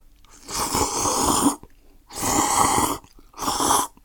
ラーメンを啜る
slurp_ramen.mp3